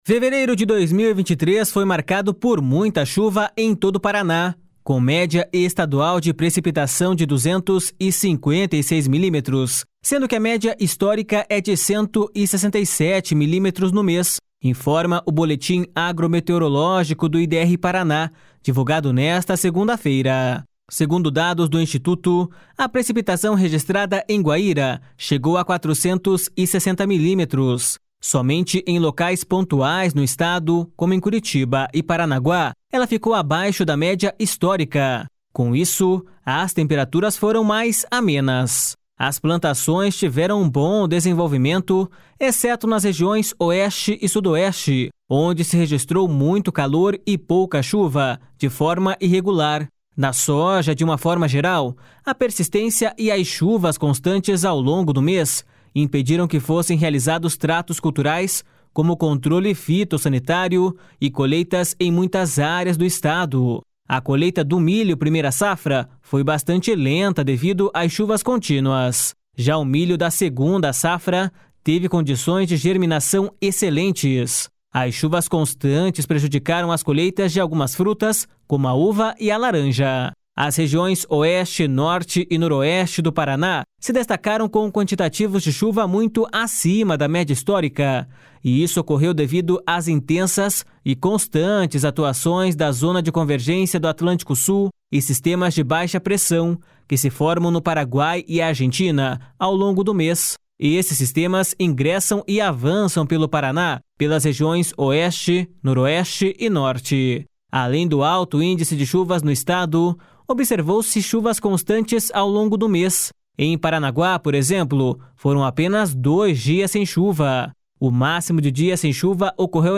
BOLETIM DO IDR PARANA.mp3